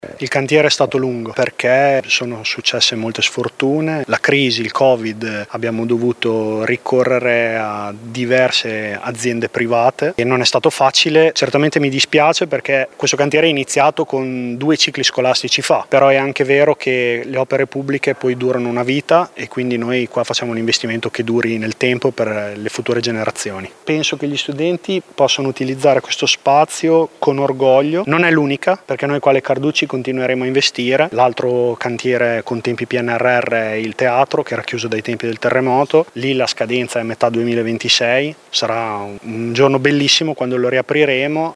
L’assessore ai lavori pubblici Giulio Guerzoni: